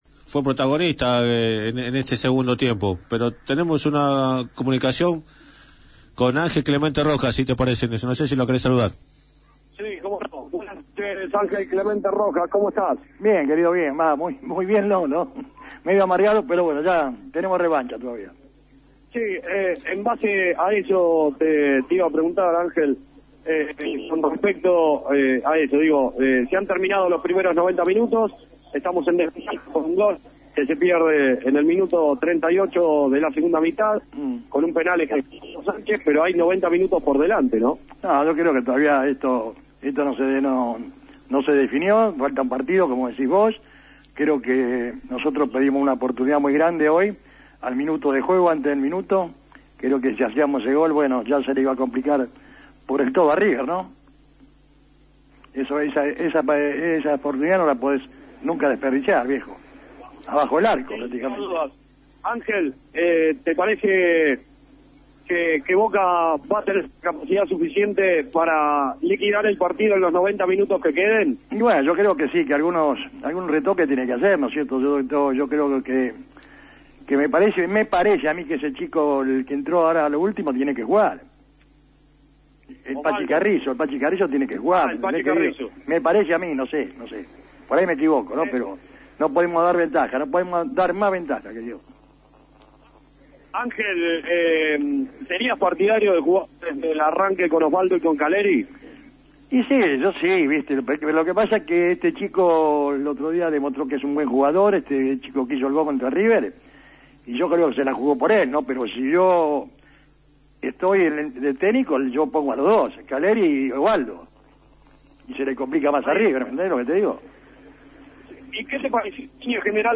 Finalizado el partido, luego de la derrota de Boca 1 a 0, entrevistaron a Angel Clemente Rojas.